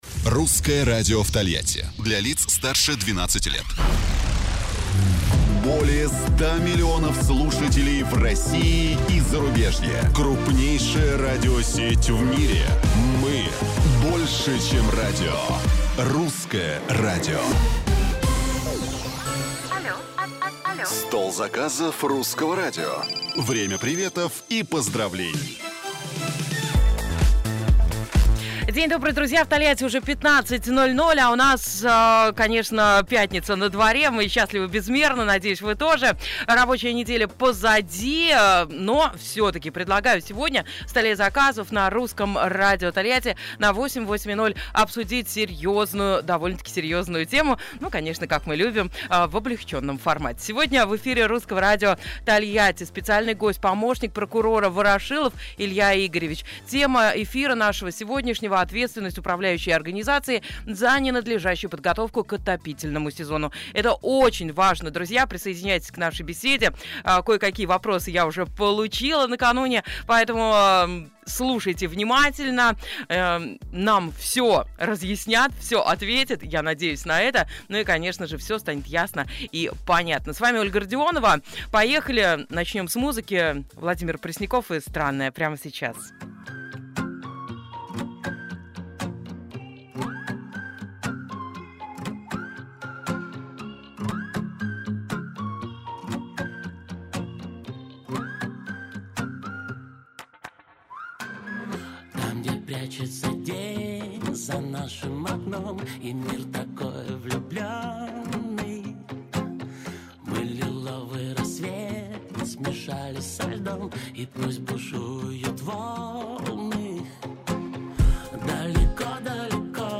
В эфире радиостанции